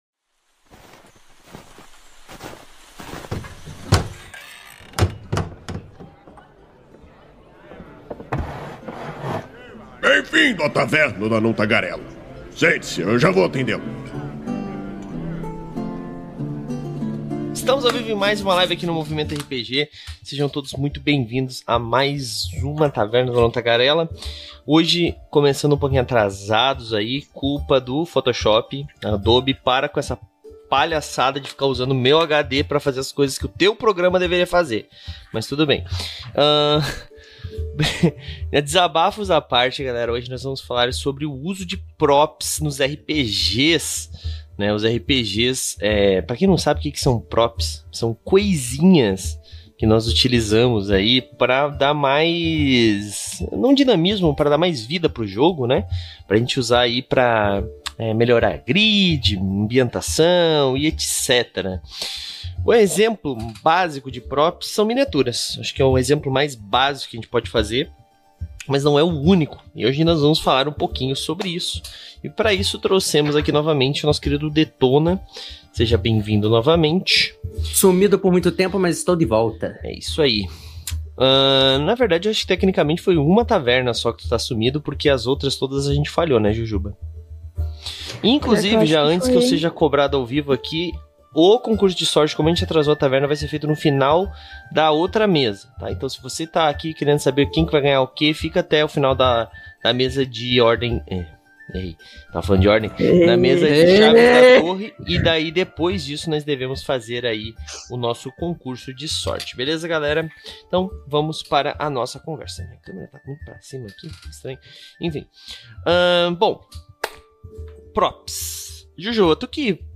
Venha entender como esses recursos a mais podem fazer diferença na sua mesa e proporcionar mais imersão para os jogadores, bem como ter novas ideias para oferecer aos seus jogadores uma experiência a mais. A Taverna do Anão Tagarela é uma iniciativa do site Movimento RPG, que vai ao ar ao vivo na Twitch toda a segunda-feira e posteriormente é convertida em Podcast.